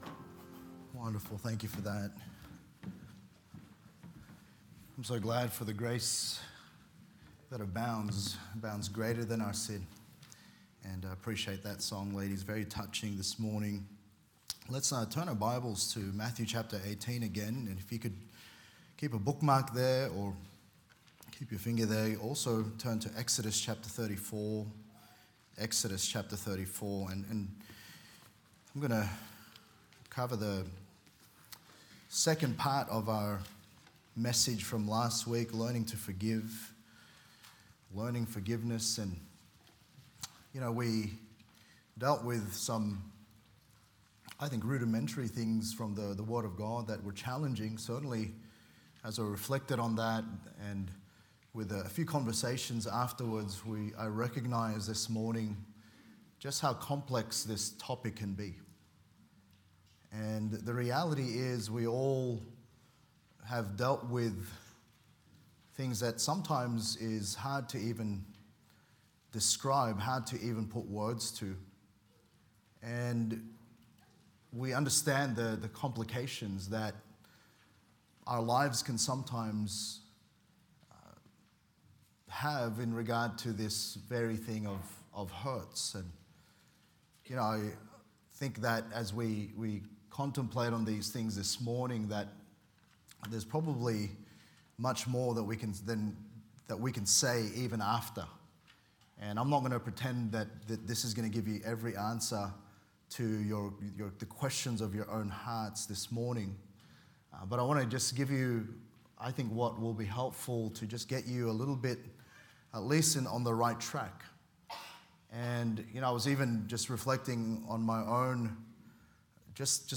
Learning Forgiveness Current Sermon